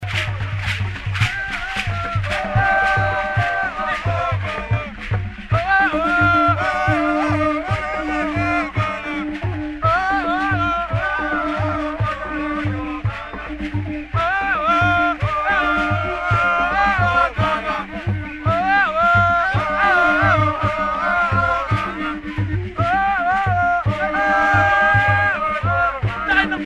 Danse chantée Bamoun
Pays Cameroun
Pièce musicale éditée